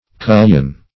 Cullion \Cul"lion\ (k?l"y?n), n.
cullion.mp3